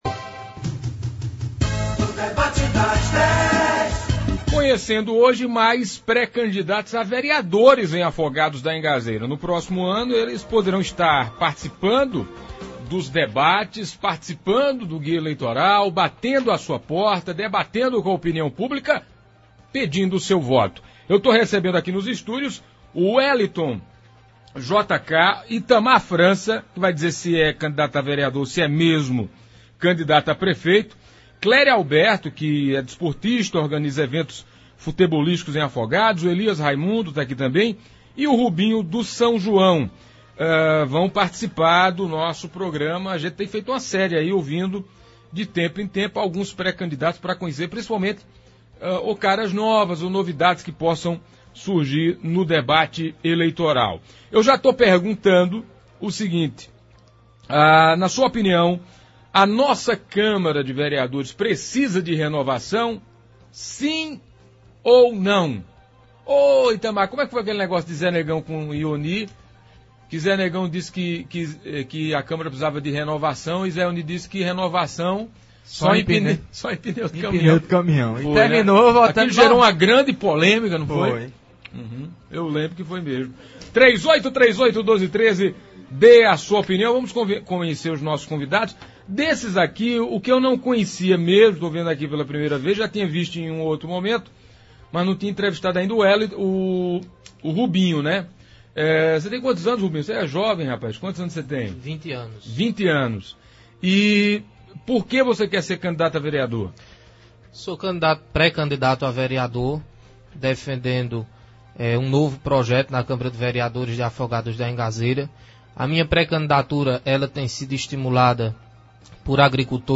Eles rebateram e questionaram um ao outro.